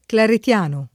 claretiano [ klaret L# no ]